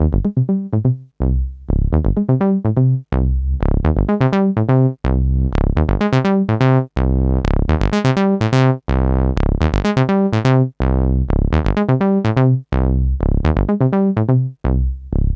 cch_acid_robocop_125.wav